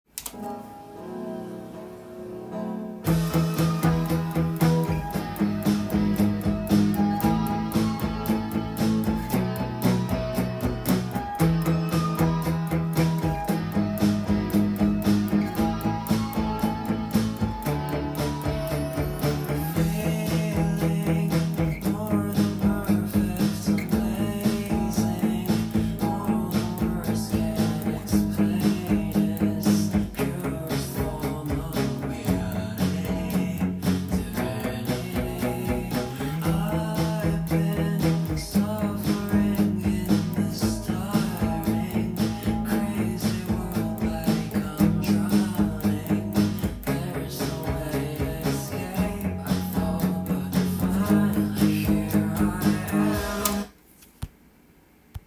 音源にあわせて譜面通り弾いてみました
ゆったりとしたリズムが印象的な8ビートの曲です。
パワーコードにするとカンタンに弾くことが出来ます。